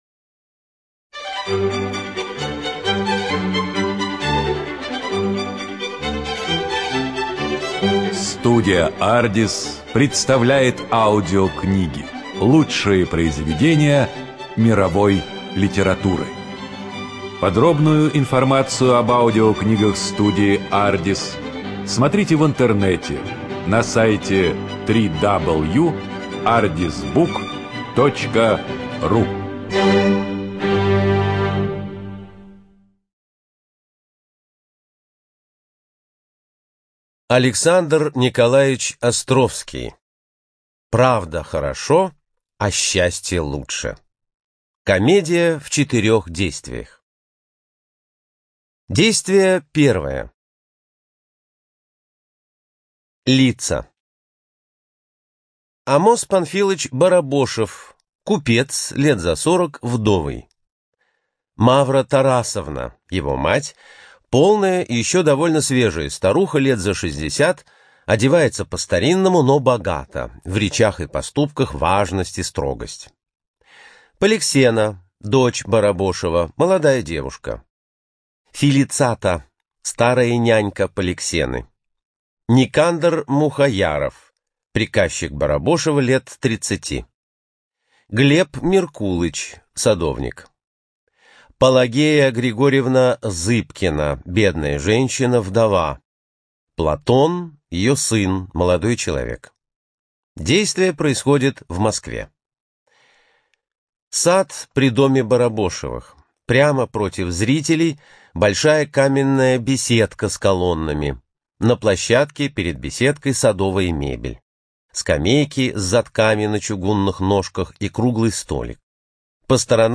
ЖанрДраматургия
Студия звукозаписиАрдис